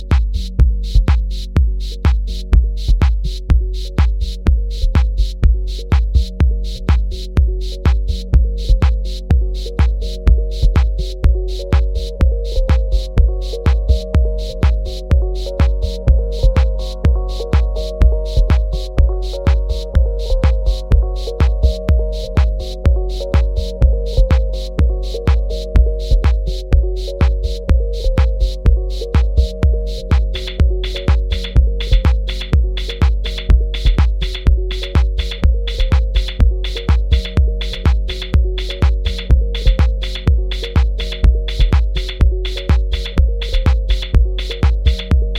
another deep burner!